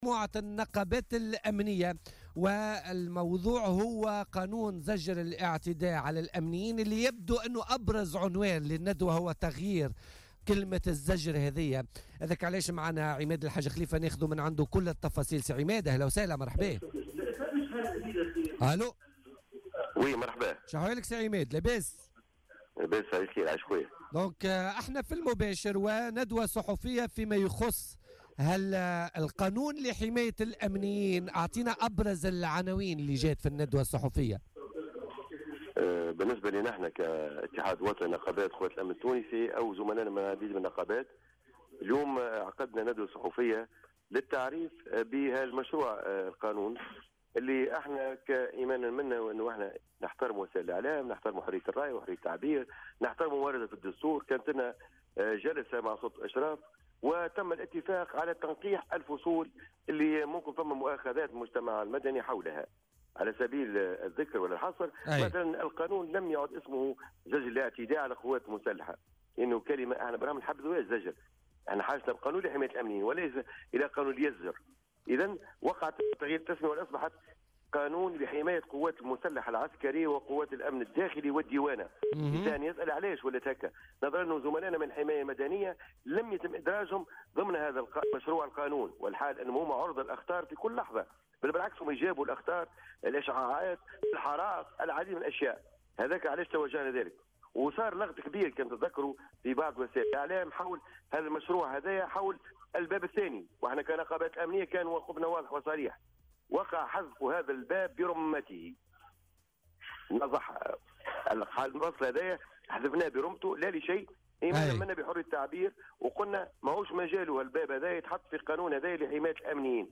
مداخلة له في بولتيكا